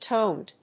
/tond(米国英語), təʊnd(英国英語)/